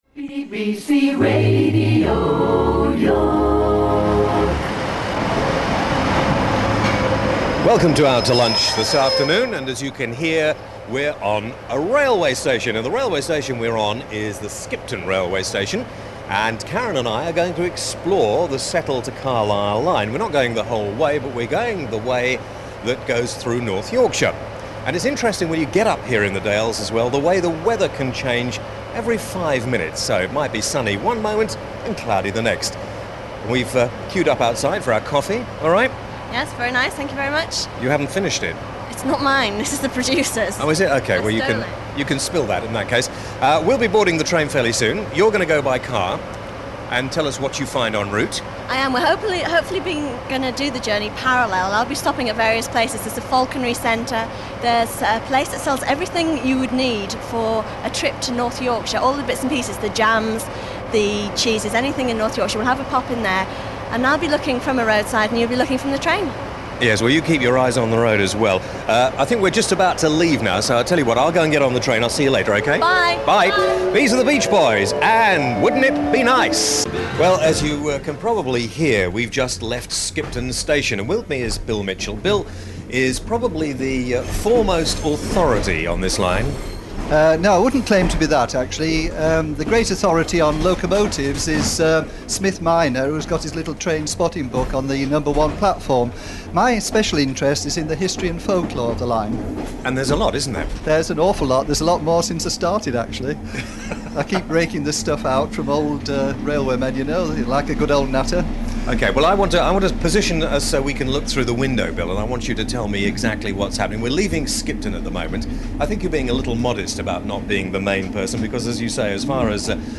In the summer of ’93 a major name in commercial radio spent a season presenting a series of Sunday shows for BBC Radio York.